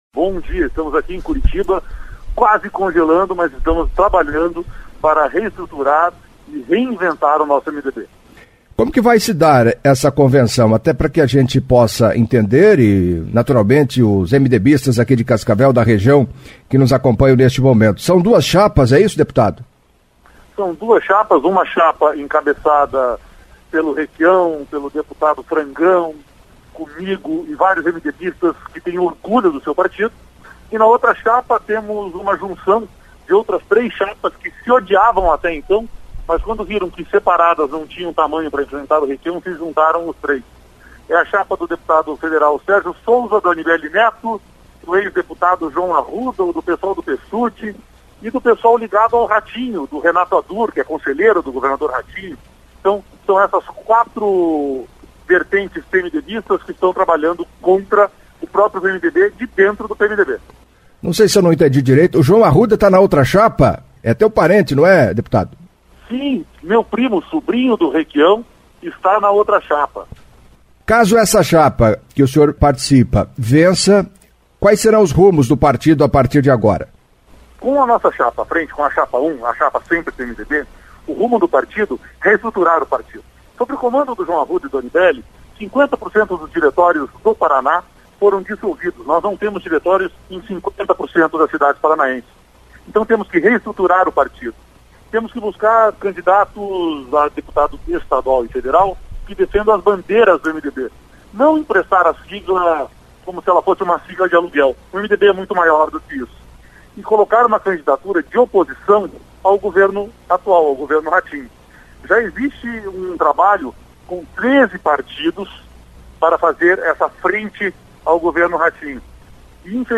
O MDB do Paraná realiza convenção estadual no proximo sábado dia 31 de julho das 9h às 13h. Em chapas opostas, o deputado estadual Requião Filho e o deputado federal Sérgio Souza participaram do CBN Cascavel 1ª Edição desta quarta-feira (28).